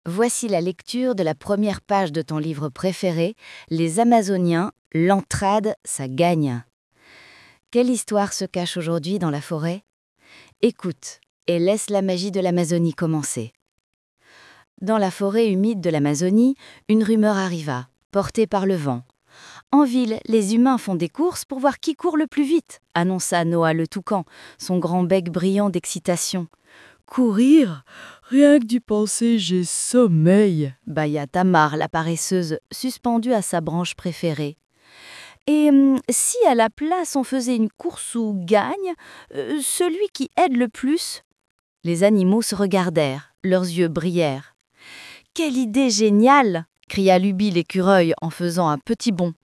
Lectures (audio)